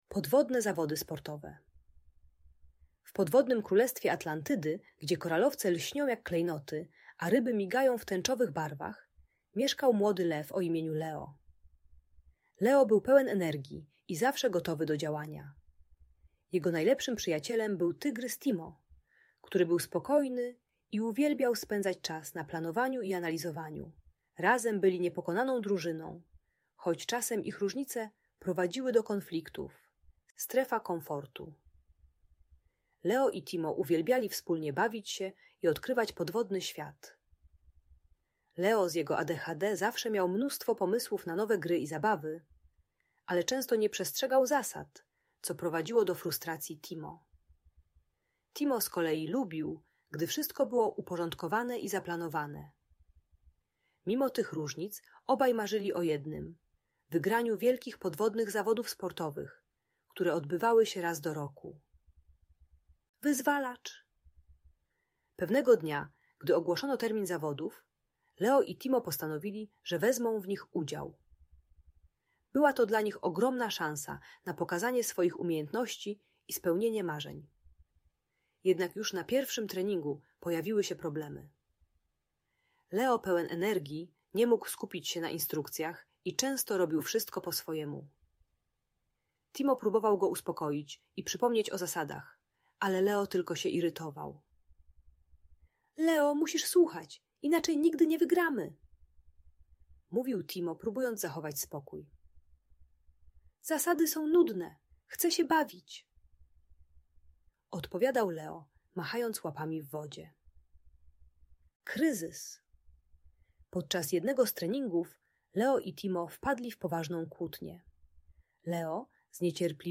Historia przyjaźni Leo i Timo w podwodnym świecie - Rodzeństwo | Audiobajka